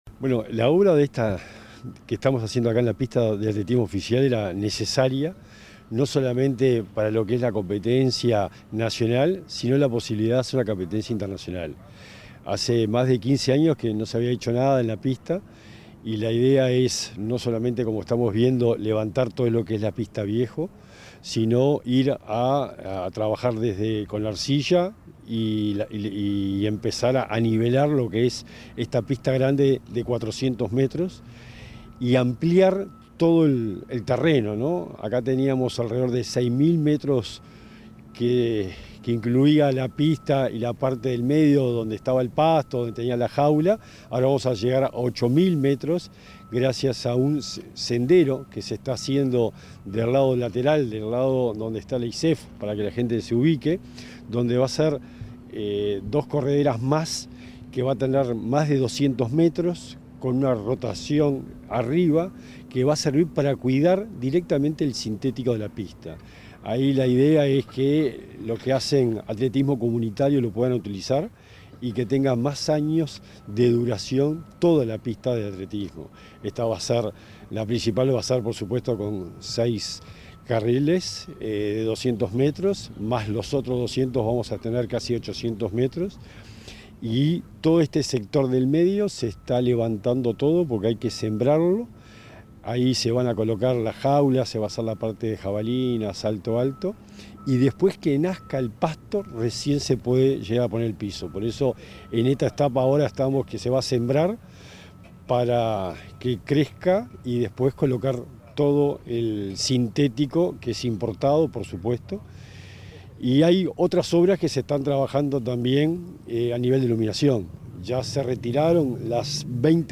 Entrevista al secretario nacional del Deporte, Sebastián Bauzá
El secretario nacional del Deporte, Sebastián Bauzá, dialogó con Comunicación Presidencial acerca de las obras en la pista de atletismo de Montevideo.